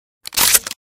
reload_loop.ogg